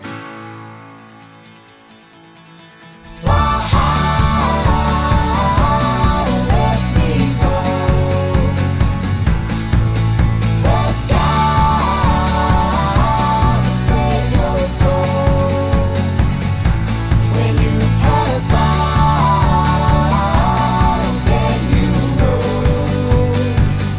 Bass Reduced Pop (2010s) 3:37 Buy £1.50